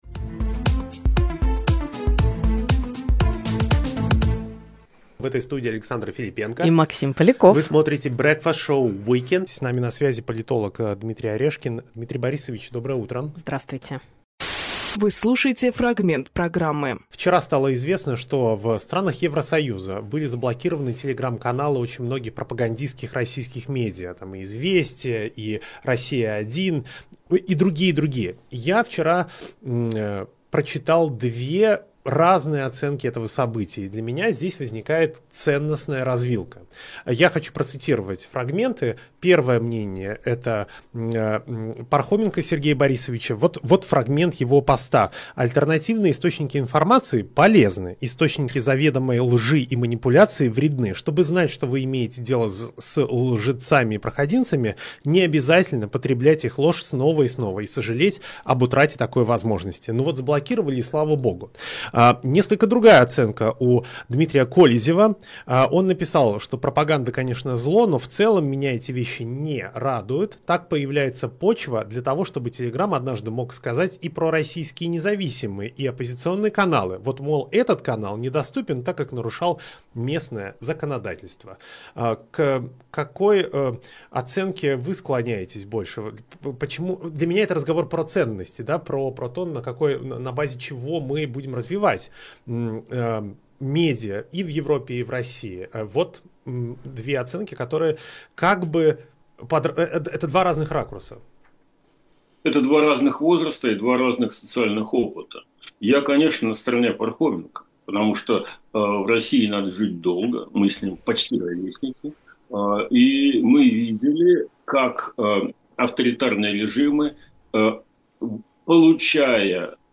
Фрагмент эфира от 29.12.24